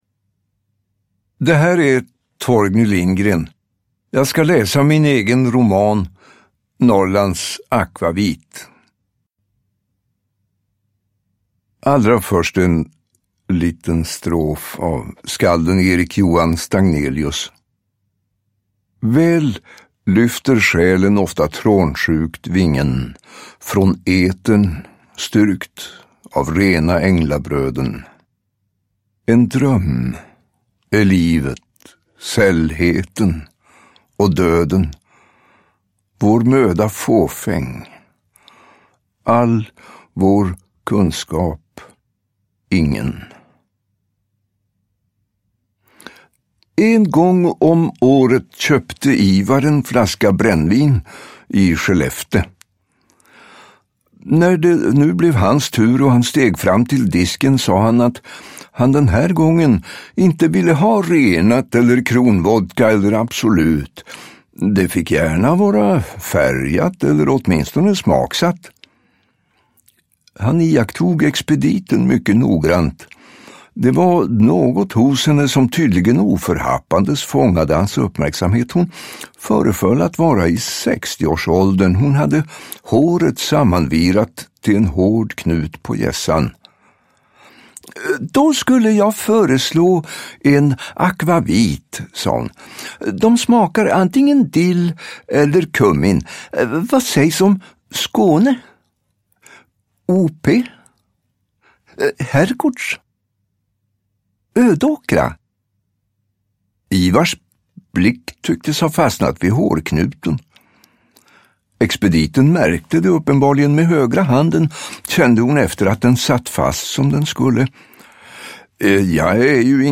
Norrlands Akvavit (ljudbok) av Torgny Lindgren